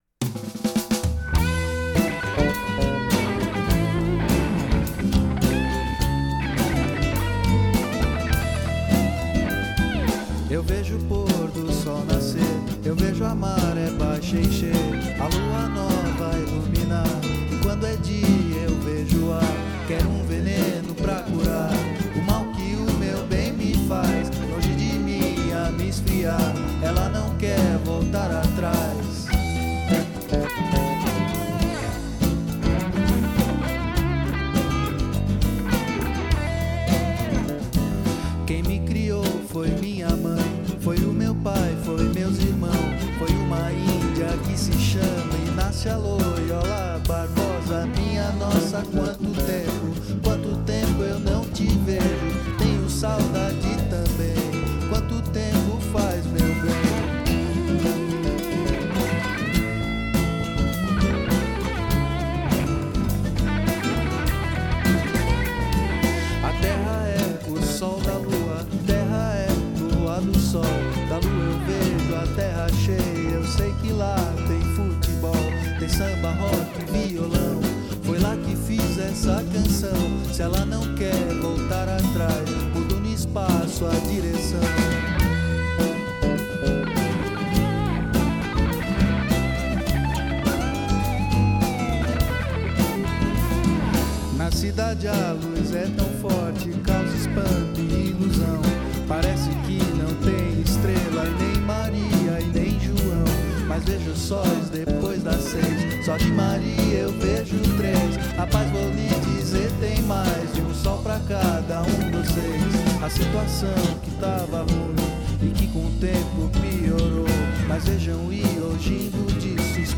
Studio Session (2003)
Here is my one and only "studio session".
• Acoustic Guitar and Vocals: Unknown.
• Bass
• Drums